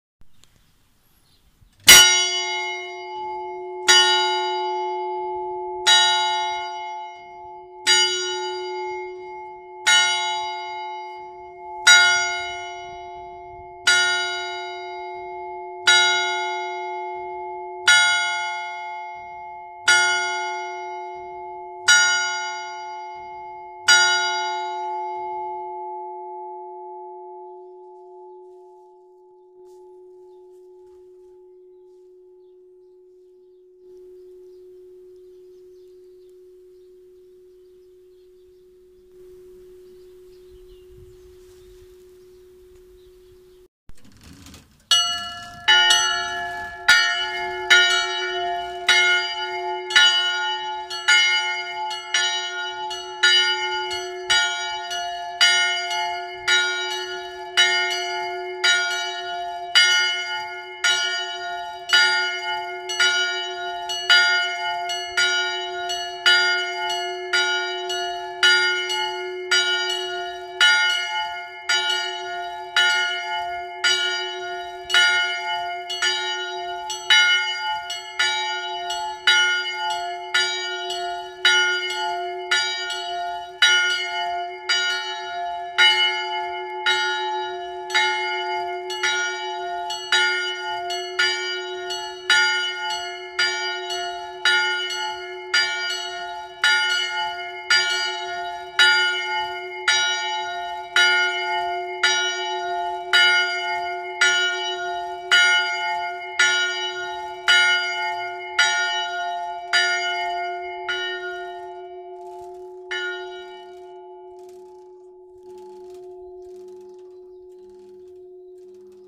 cloche (n°1) - Inventaire Général du Patrimoine Culturel